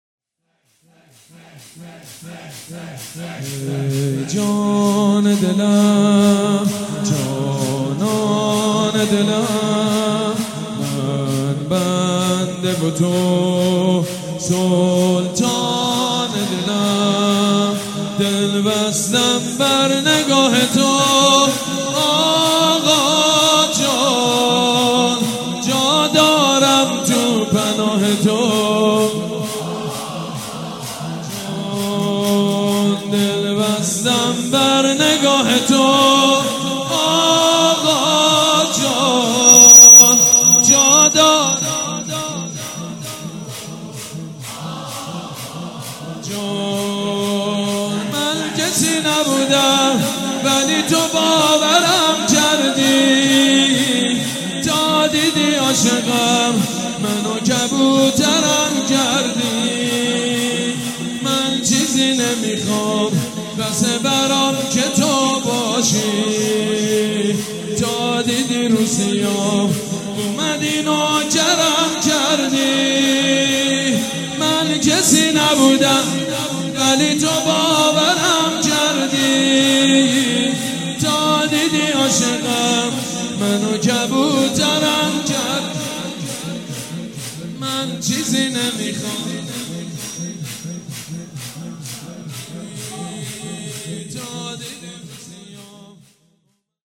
«شهادت امام جواد 1394» شور: ای جان دلم جانان دلم